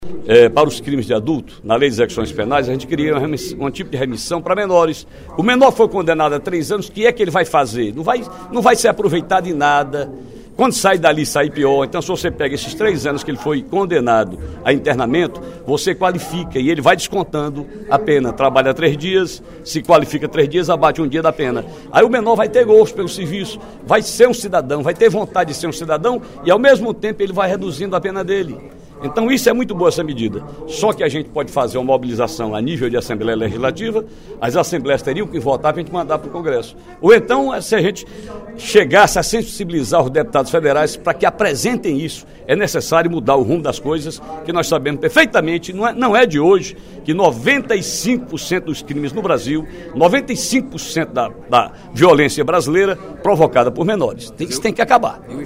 O deputado Ferreira Aragão (PDT) defendeu, durante o primeiro expediente da sessão plenária desta quarta-feira (15/02), medida que permite a remissão de pena de adolescentes em conflito com a lei por meio de trabalho e educação.
Dep. Ferreira Aragão (PDT) Agência de Notícias da ALCE